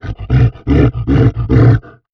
MONSTER_Exhausted_08_mono.wav